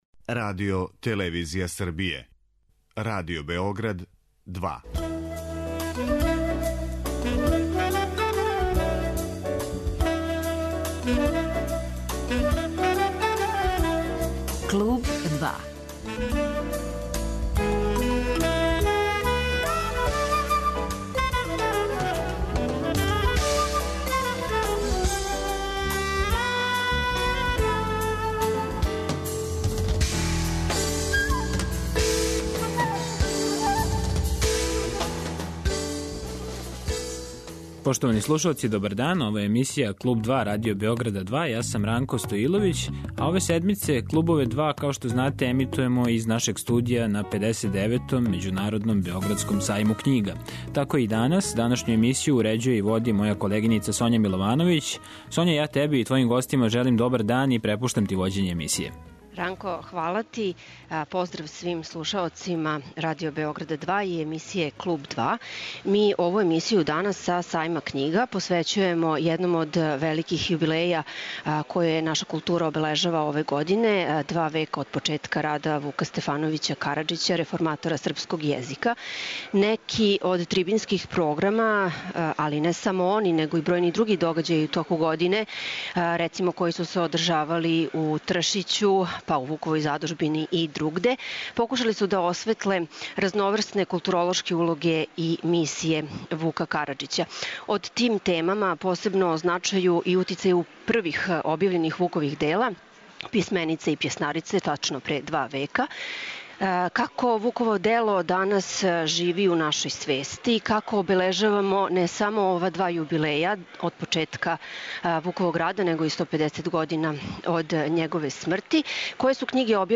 'Клуб 2', који емитујемо уживо са 59. Београдског сајма књига, посвећујемо једном од великих јубилеја које наша култура обележава ове године - два века од почетка рада Вука Стефановића Караџића, реформатора српског језика.